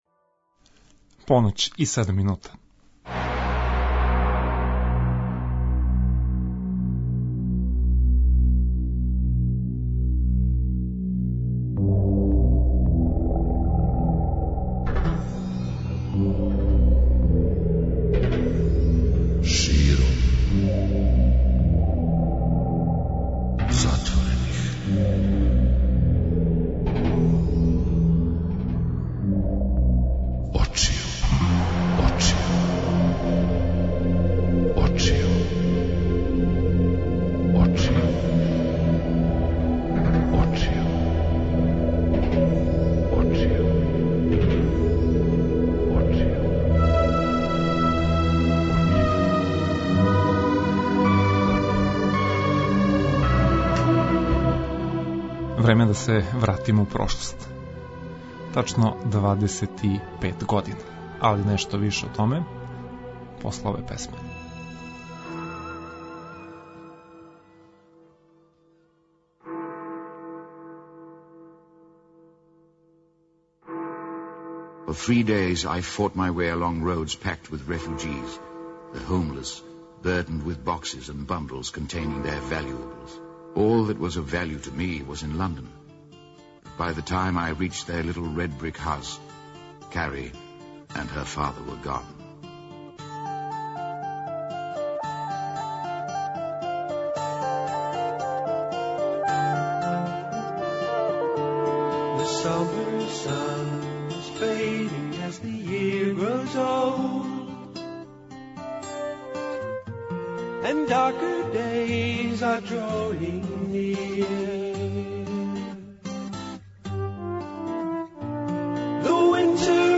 Уз све то подсетићемо се чувених музичких тема и инстурментала из филмова и тв-серија које смо гледали и слушали у Погледу из свемирског брода.
преузми : 55.60 MB Широм затворених очију Autor: Београд 202 Ноћни програм Београда 202 [ детаљније ] Све епизоде серијала Београд 202 Говор и музика Састанак наше радијске заједнице We care about disco!!!